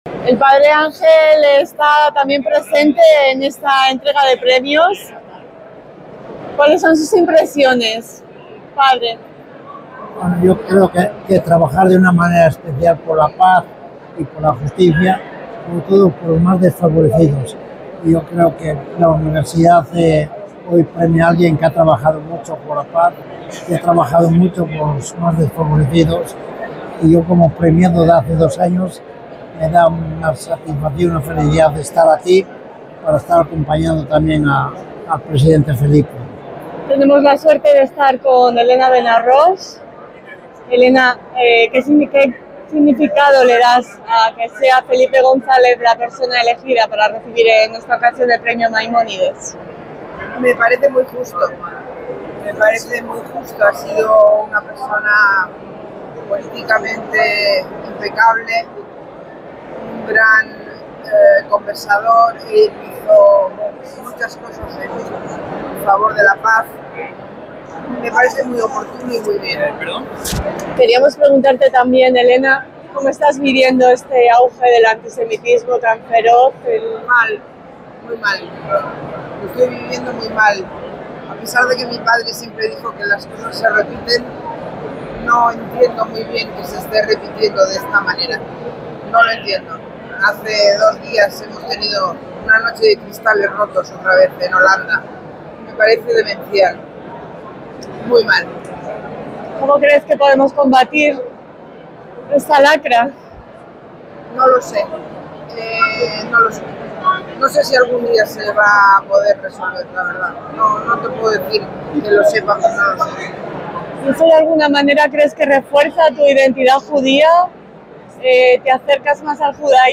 EL REPORTAJE